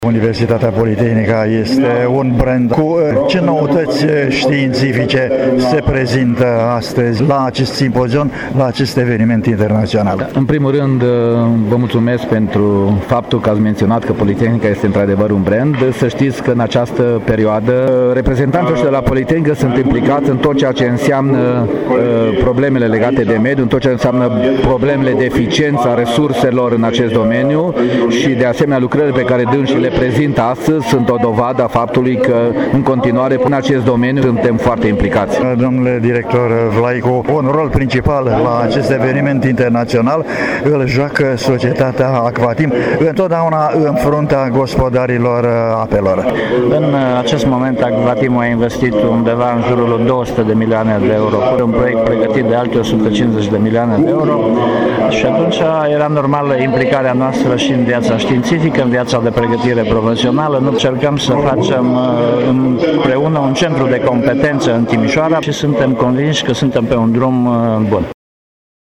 EcoImpulsLa Universitatea Politehnica din Timişoara se desfăşoară cea de-a II-a ediţie a Conferinţei Internaţionale EcoImpuls, o manifestare ştiinţifică de prestigiu, vizând apa. La conferinţă participă, cu standuri expoziţionale şi echipamente de specialitate, personalităţi din domeniul cercetării din Timişoara şi oaspeţi de peste hotare.